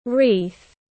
Wreath /riːθ/